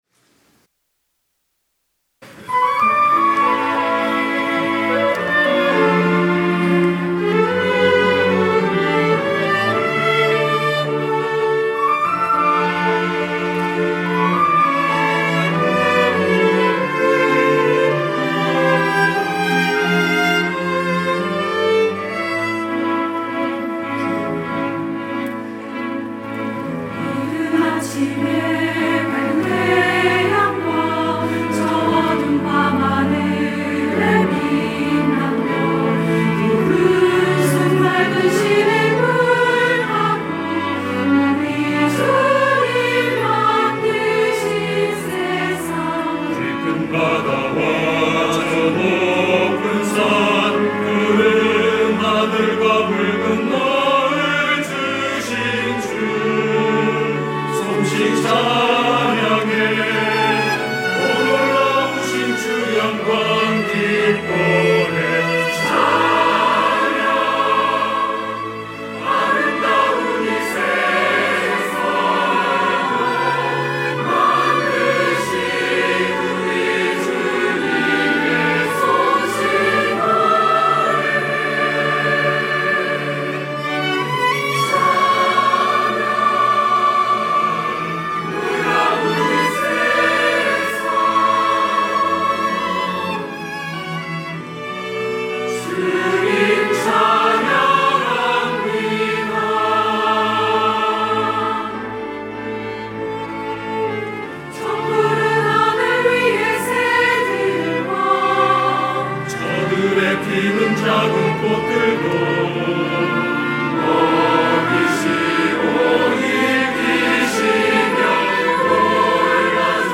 호산나(주일3부) - 주님 만드신 세상
찬양대